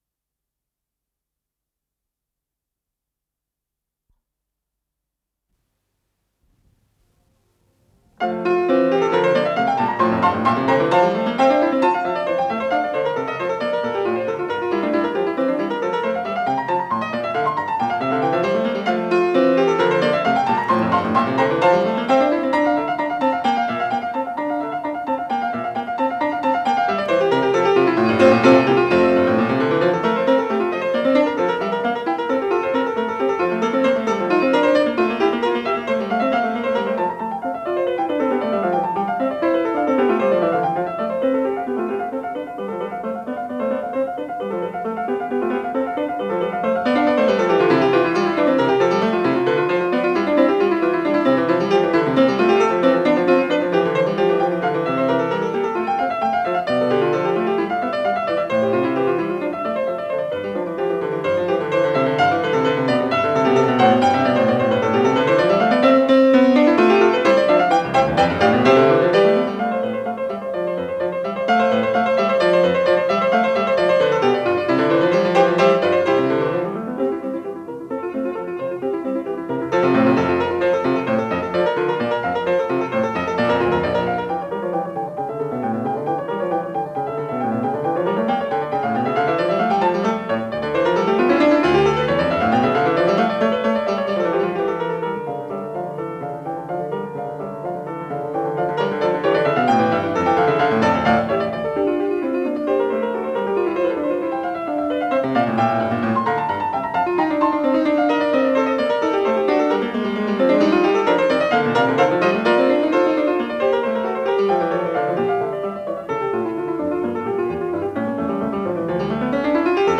с профессиональной магнитной ленты
ПодзаголовокФа мажор
Скорость ленты38 см/с